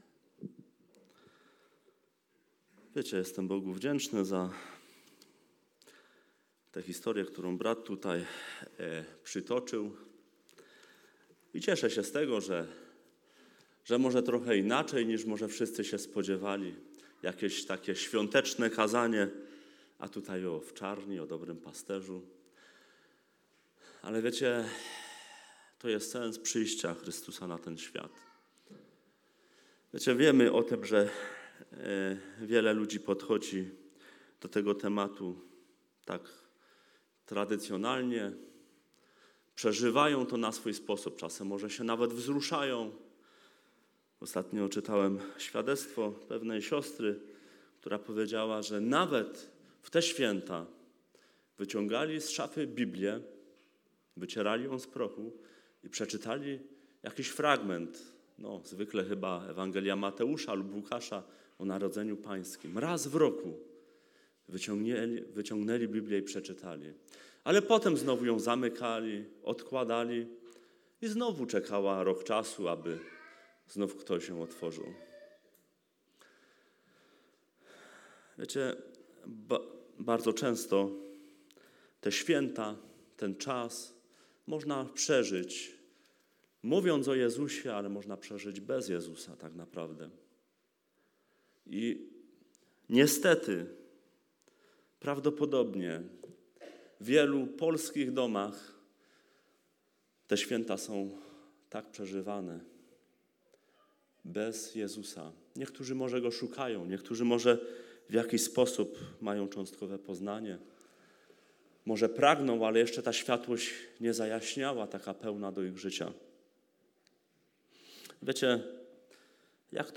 [audioalbum title=”Nabożeństwo” detail=”Boże Narodzenie” date=”25.12.2017″]